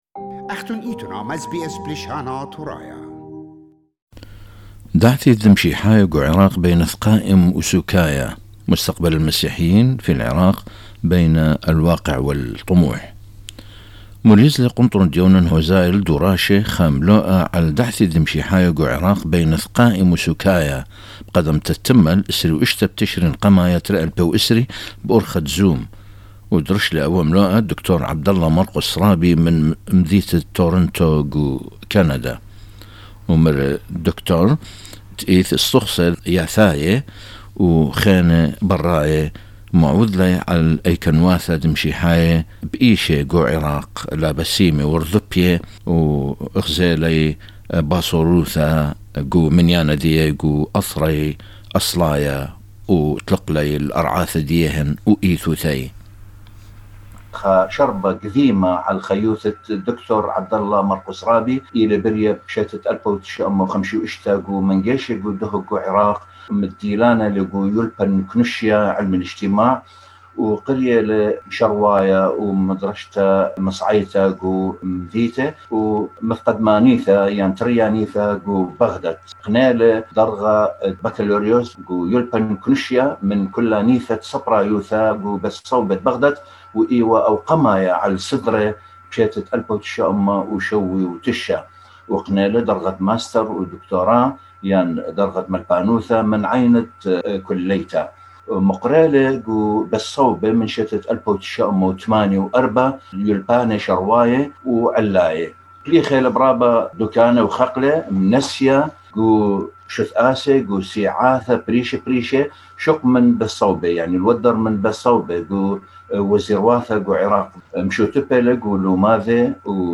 The lecture was live via zoom.